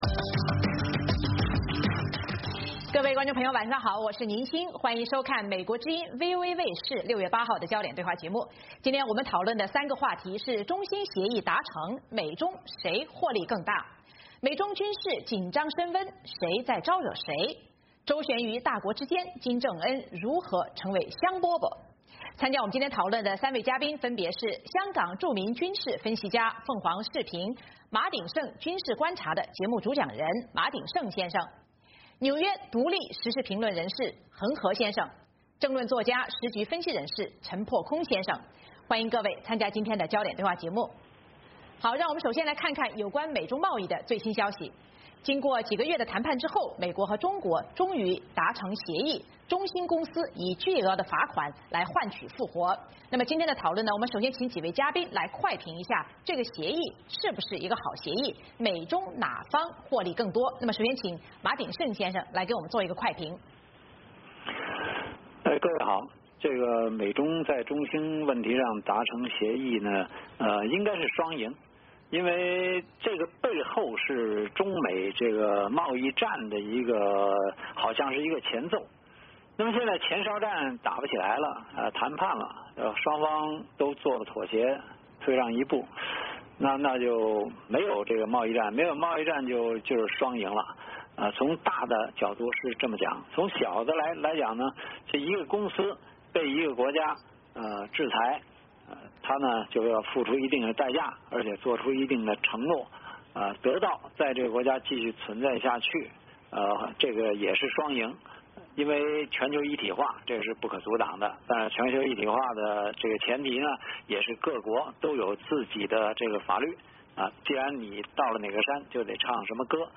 经过几个月的谈判之后，美国和中国终于达成协议，中兴公司以巨额罚款换取复活。今天的讨论我们首先请几位嘉宾来快评一下，这个协议是不是一个好协议？